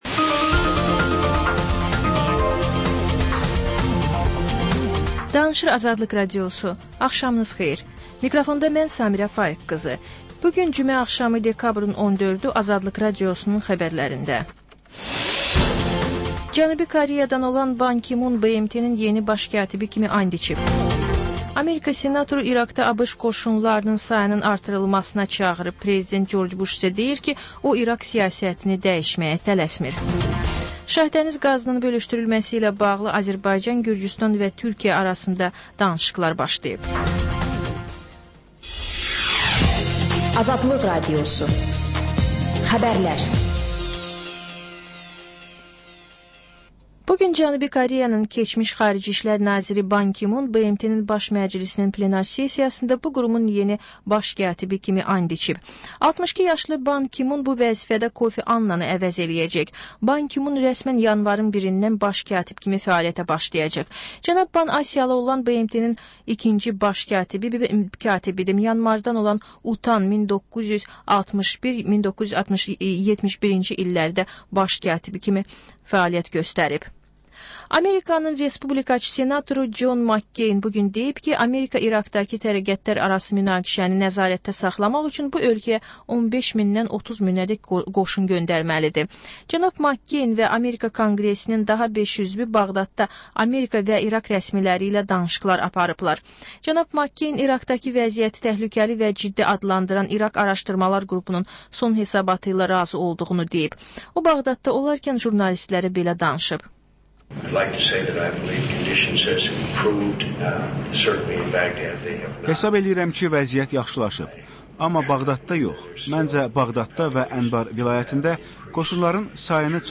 Xəbər, reportaj, müsahibə. Sonra: Və ən son: Qlobus: xaricdə yaşayan azərbaycanlılar.